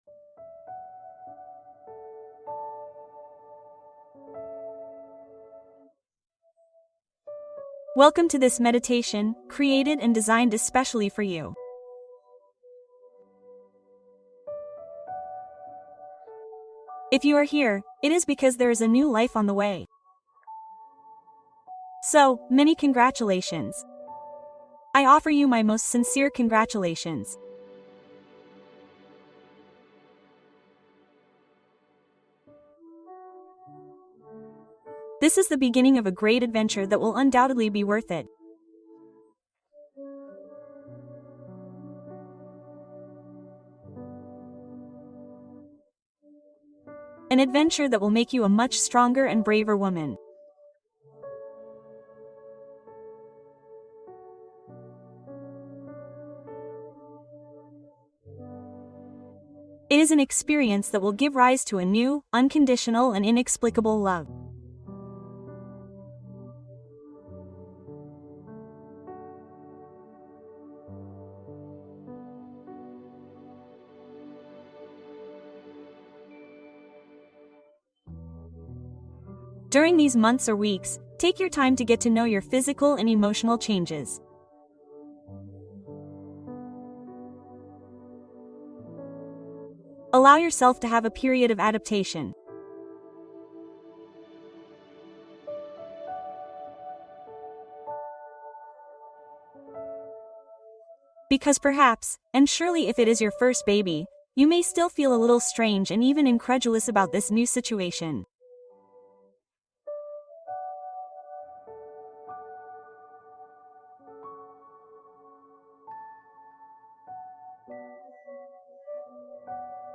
Meditación especial para embarazadas | Relajación profunda para el embarazo